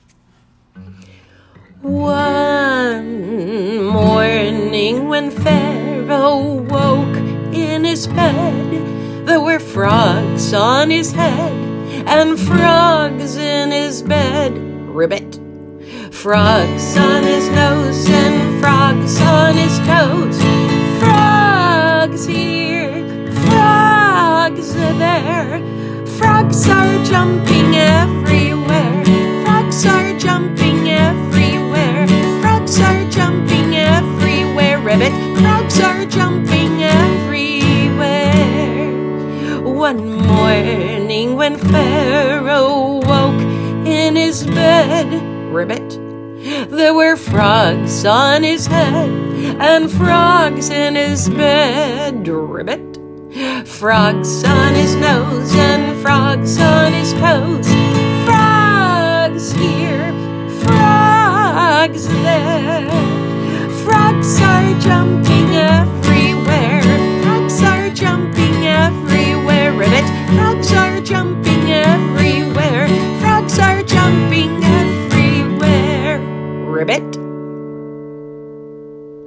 5-the-frog-song-children-for-after-meal-singalong-pg-31-a-family-haggadah.m4a